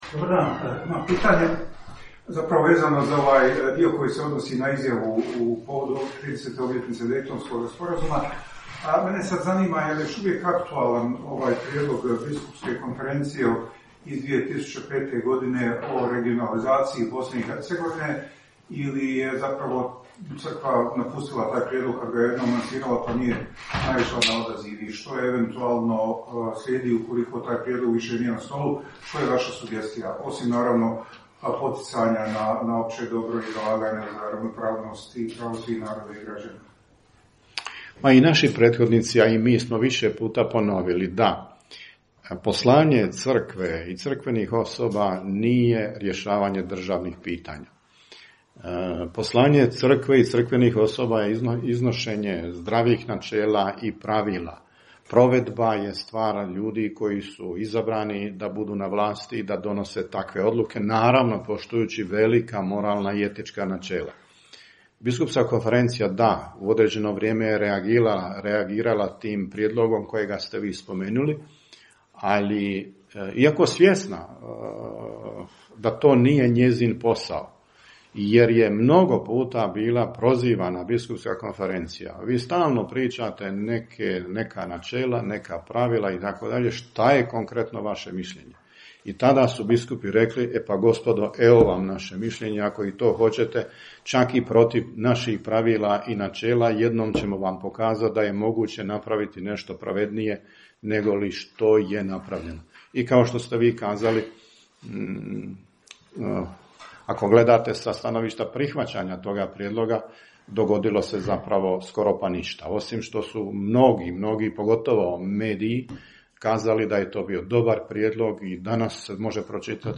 VIDEO: Održana konferencija za medije na kraju 94. redovitog zasjedanja BK BiH u Sarajevu
Uslijedila su zatim pitanja novinara.